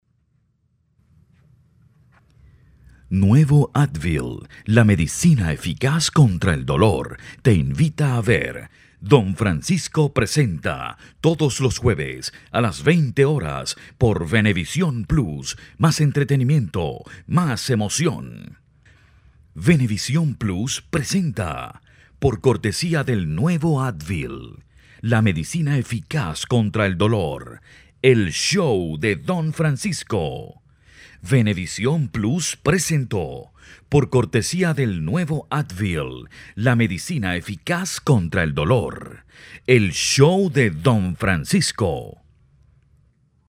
Locutor comercial, E learning y corporativo versátil
Sprechprobe: eLearning (Muttersprache):